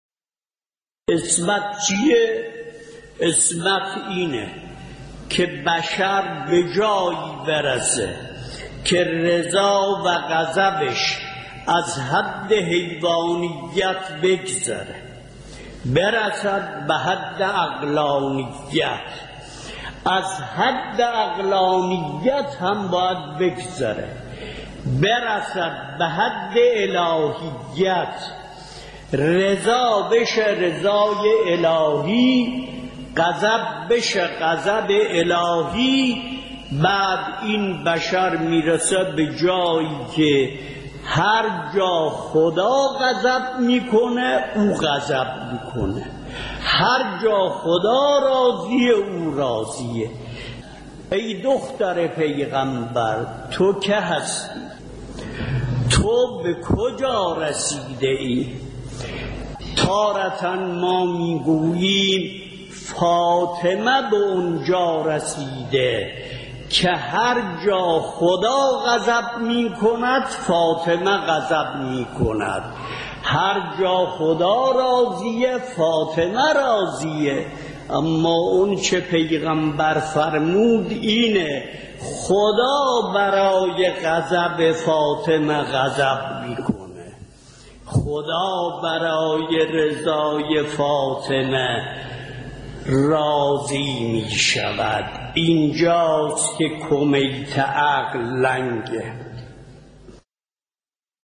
به مناسبت ایام فاطمیه مجموعه پادکست «نگین شکسته» با هدف بیان بندگی و فضائل حضرت فاطمه زهرا(س) با کلام اساتید بنام اخلاق به کوشش ایکنا گردآوری و تهیه شده است، که سی‌امین قسمت این مجموعه با کلام آیت الله وحید خراسانی با عنوان «خدا برای رضای فاطمه(س) راضی می‌شود» تقدیم مخاطبان گرامی ایکنا می‌شود.